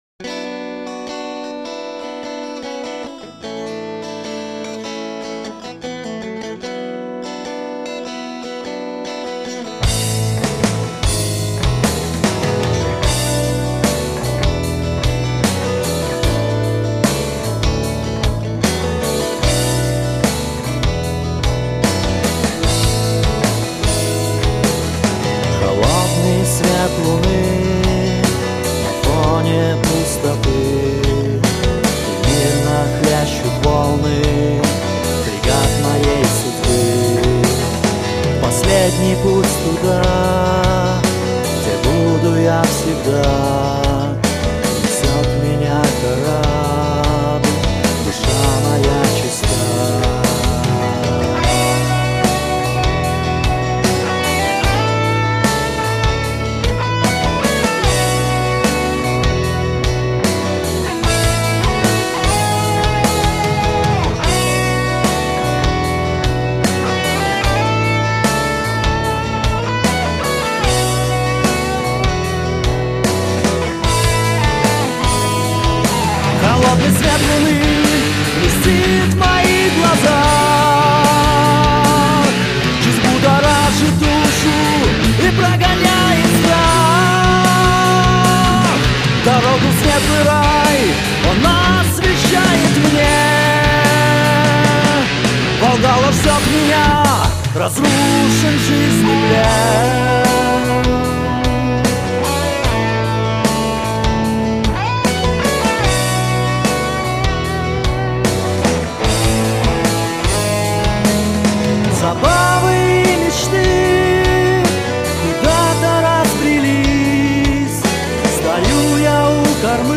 Альбом записан в стиле heavy metal, тексты на русском языке.
ударные
бас
ритм-гитара
соло-гитара, бэк-вокал
вокал